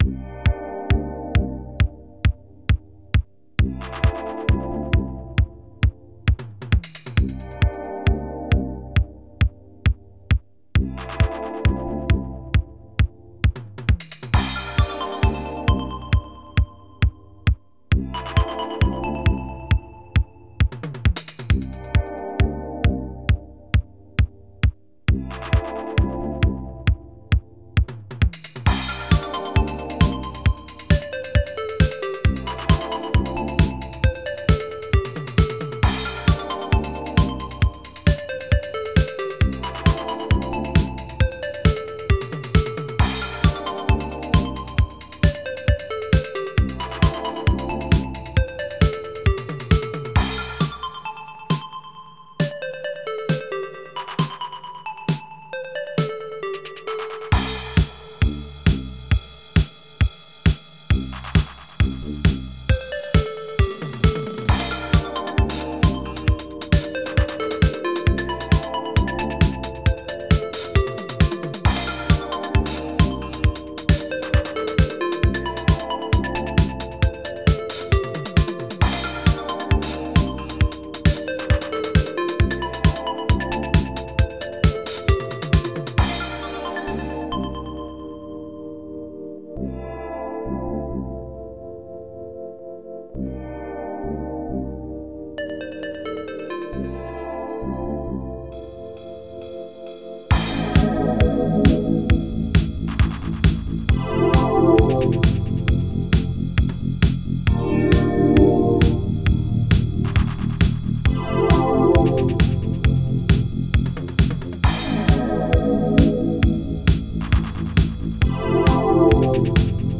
Is this… Is this what Synthwave is?